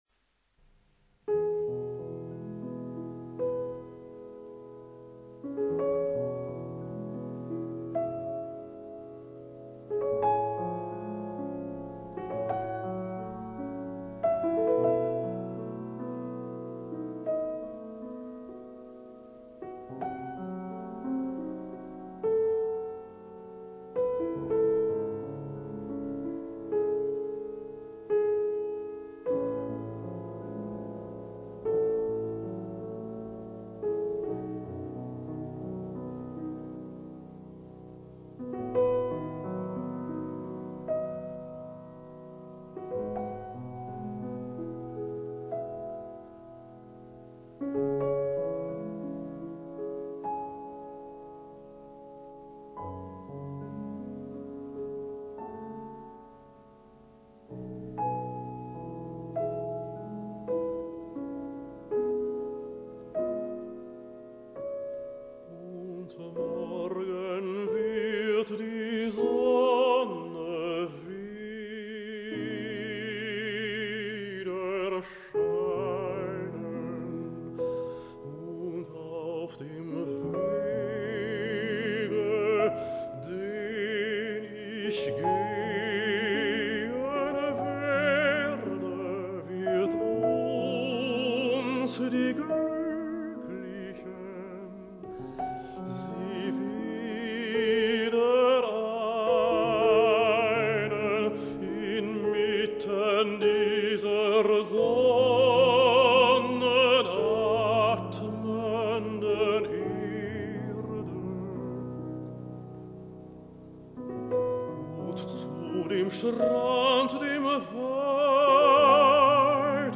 马凯词 理查斯特劳斯曲 苏哉唱 包德温钢琴伴奏 歌词大意： 明日太陽將再次照耀， 而我將要行走的小徑上， 幸運的我們將再次結合 在這樣光普照的大地上……
整首歌有著最美妙的伴奏, 在 43個小節中, 伴奏佔了 20小節. 它從最深處滲出最純潔, 最高貴的愛, 在那美麗的充滿快意的樂句中, 唱与伴奏相襯地在最安靜的情緒中開始, 也在同樣的情緒中結束. 里面沒有激動的高潮, 更沒有扣人心弦的狂熱, 但在灵魂的深處喊出至愛之呼聲...........